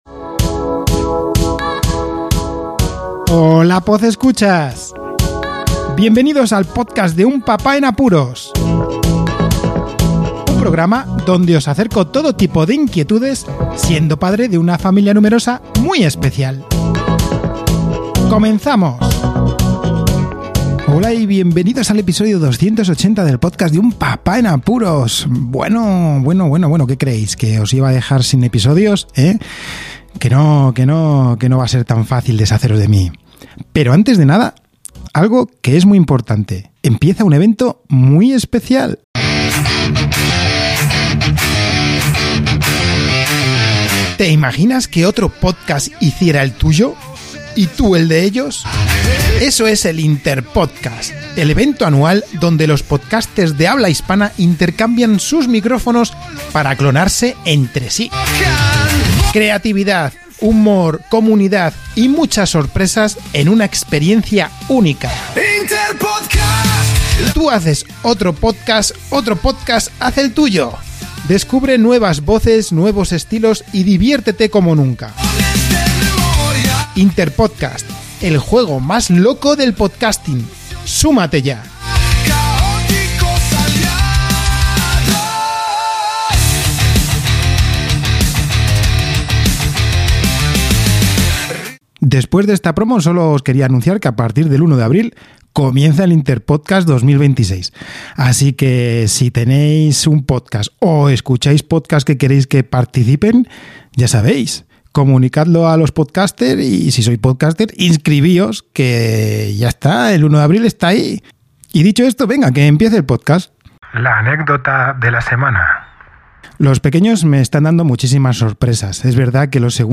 Un programa de podcasting que se realiza en completa movilidad y sin guión, cosa que me meterá en más apuros de los que ya tengo.